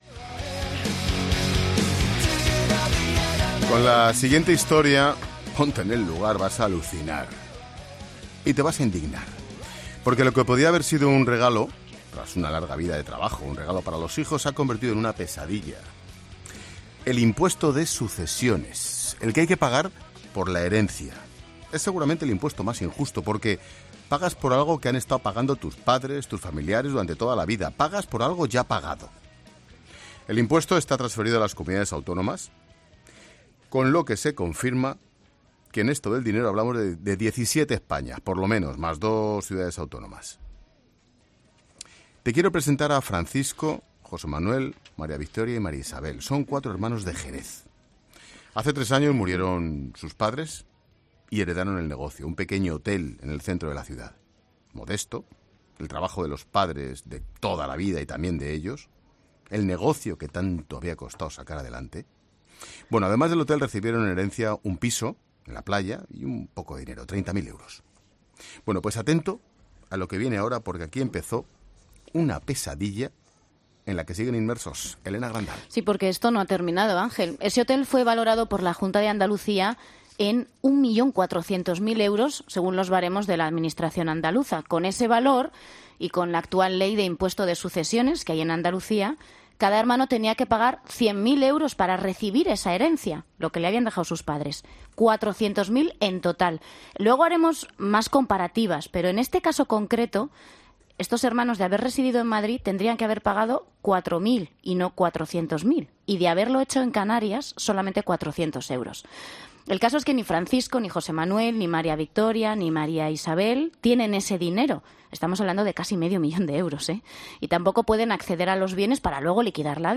Escucha la entrevista completa sobre el impuesto de sucesiones en 'La Tarde'